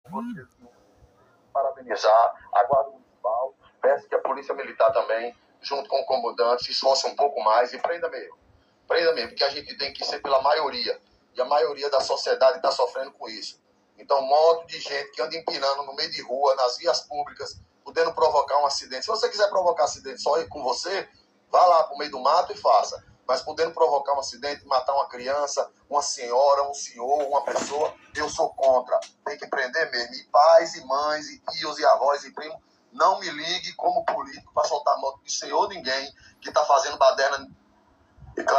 No áudio, ele parabeniza a atuação da Guarda Municipal e faz um apelo para que a Polícia Militar intensifique as ações de fiscalização e apreensão de veículos irregulares.